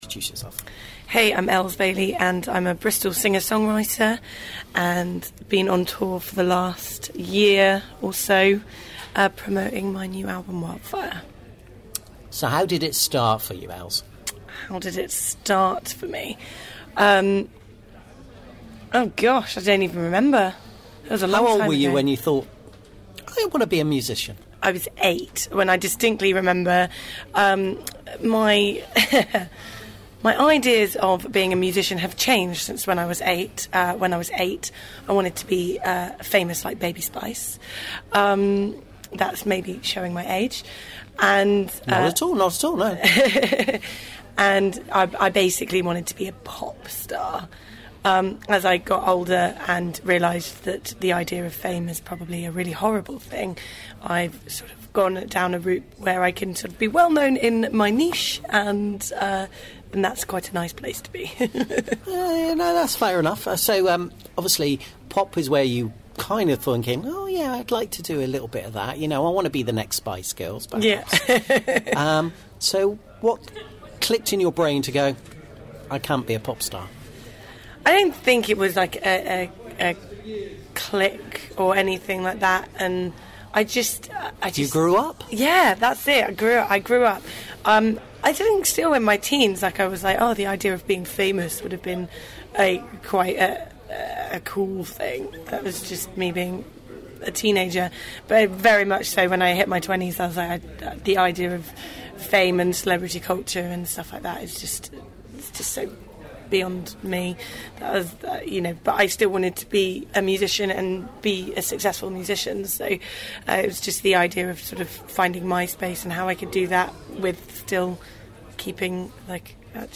Industry Interviews & Talks